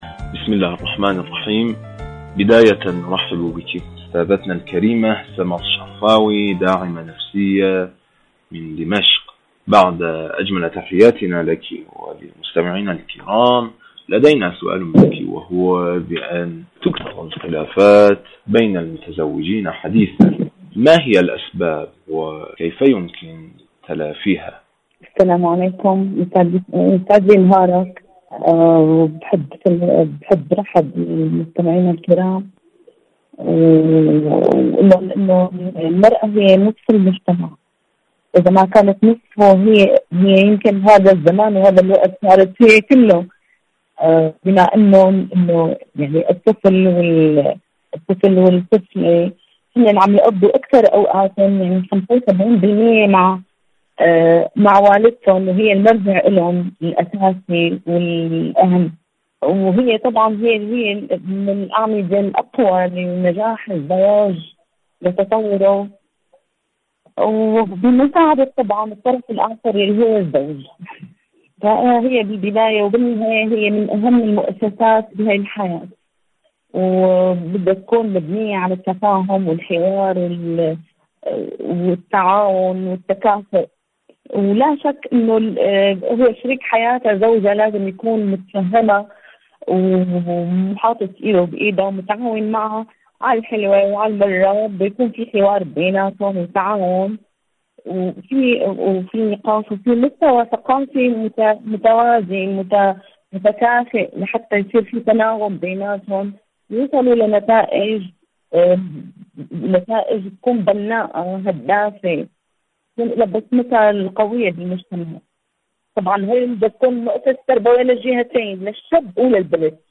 إذاعة طهران-عالم المرأة: مقابلة إذاعية